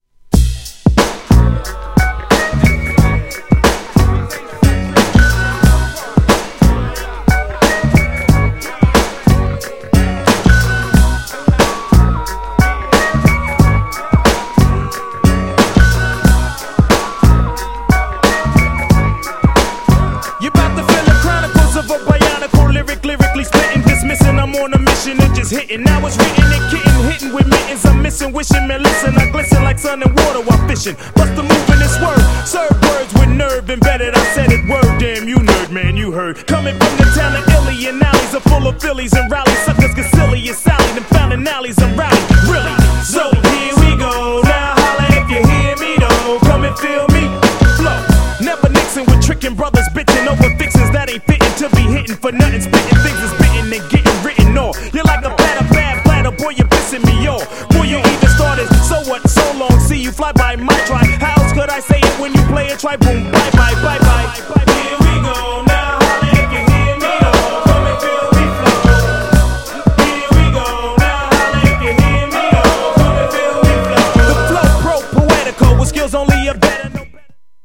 彼ららしい万人ウケ確実なPOPでダンサブルなPARTY TUNE!!
全然雰囲気違います!!
GENRE Hip Hop
BPM 91〜95BPM